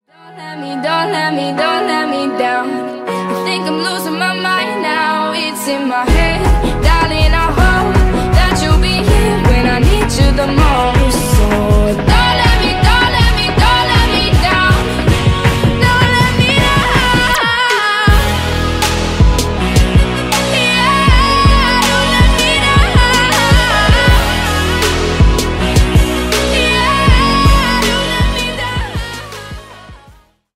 бесплатный рингтон в виде самого яркого фрагмента из песни
Поп Музыка
спокойные